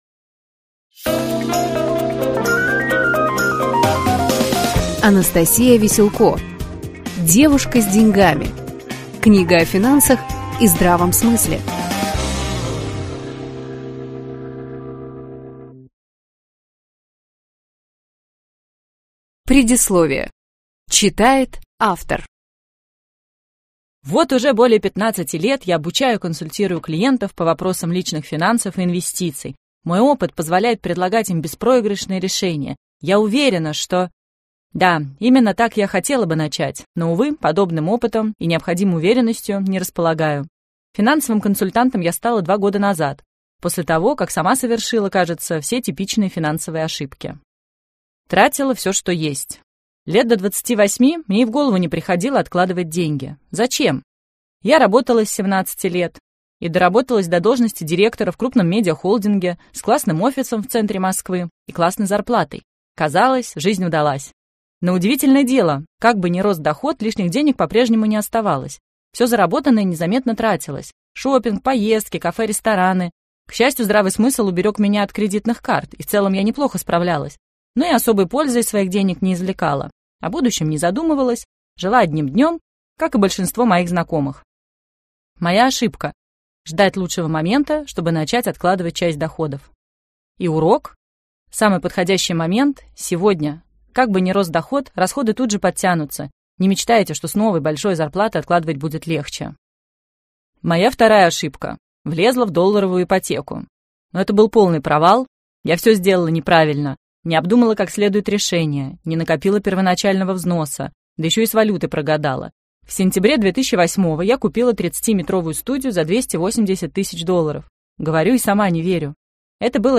Прослушать фрагмент аудиокниги Девушка с деньгами Анастасия Веселко Произведений: 1 Скачать бесплатно книгу Скачать в MP3 Вы скачиваете фрагмент книги, предоставленный издательством